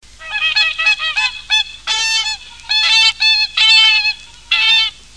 Cygnes chanteurs
Cygnus cygnus